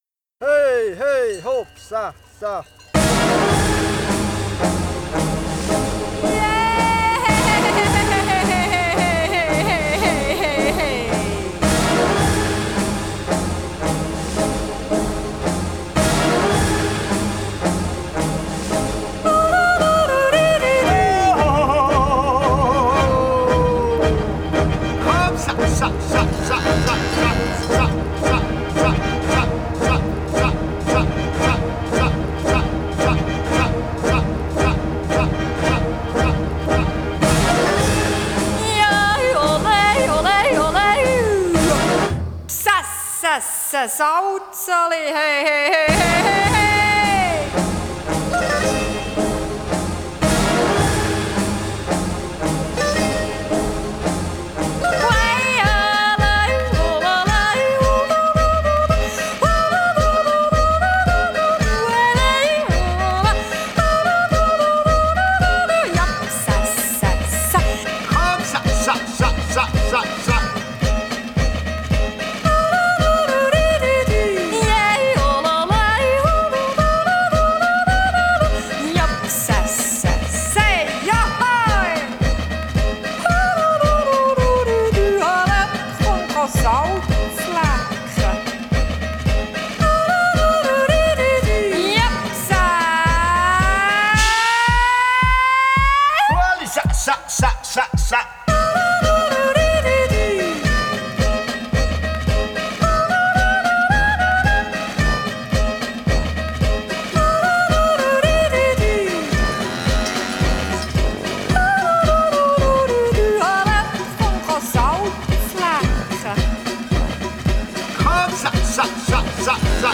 Genre: Folk / Country / Retro / Yodel